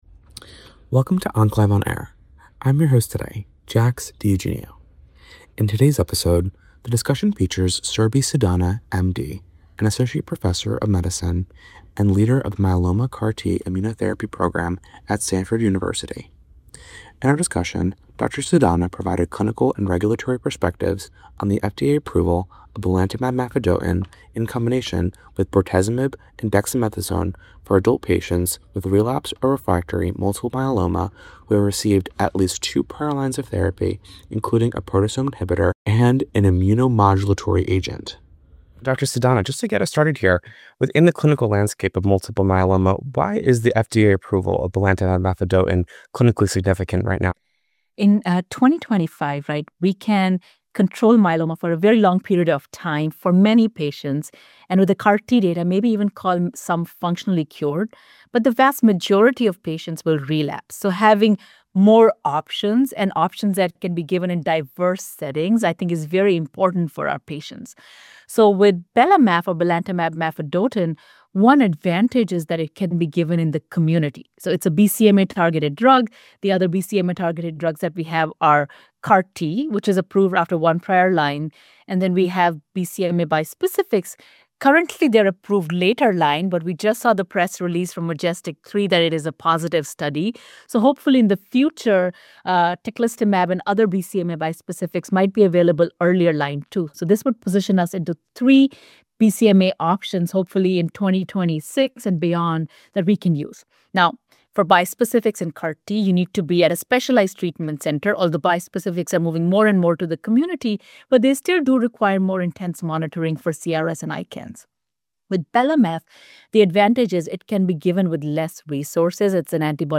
In OncLive® On Air, you can expect to hear interviews with academic oncologists on the thought-provoking oncology presentations they give at the OncLive® State of the Science Summits. The topics in oncology vary, from systemic therapies, surgery, radiation therapy, to emerging therapeutic approaches in a particular type of cancer.